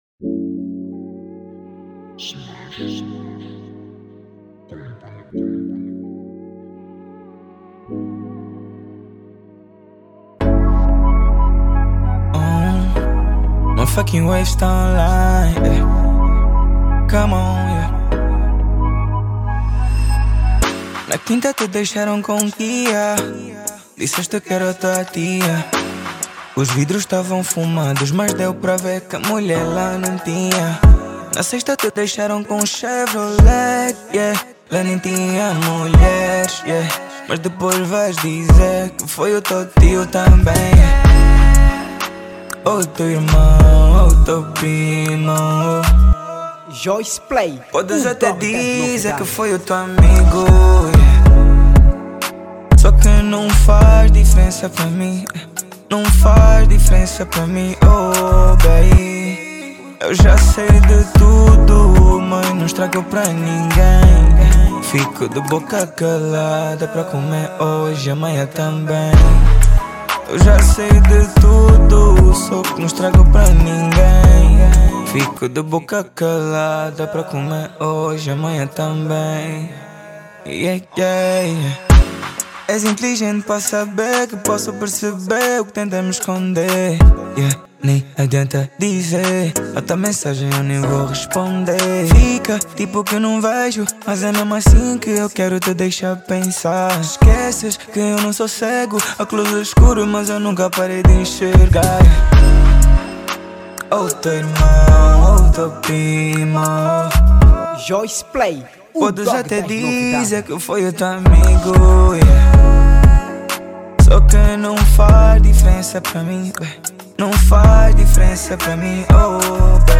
Género: Afro Pop